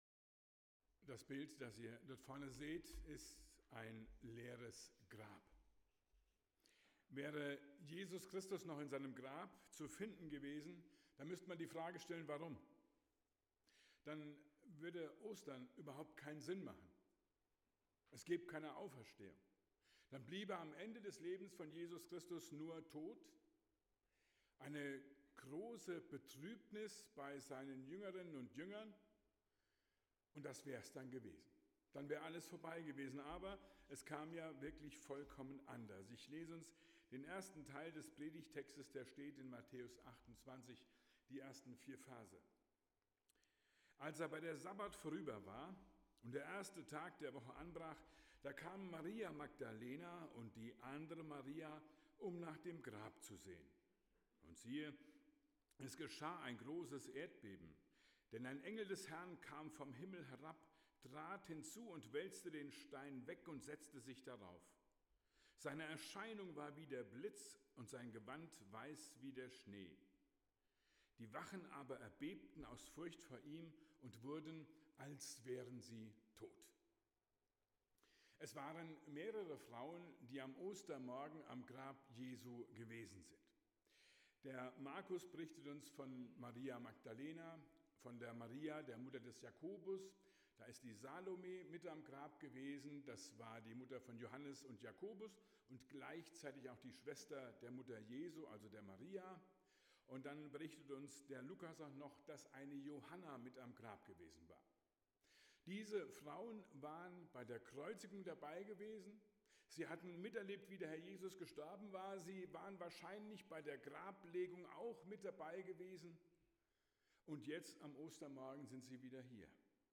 Predigt zu Ostern 2026